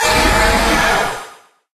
Cri de Paragruel dans Pokémon HOME.